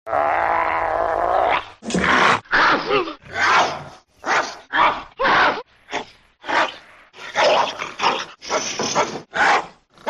curly barkingg
curly-barkingg.mp3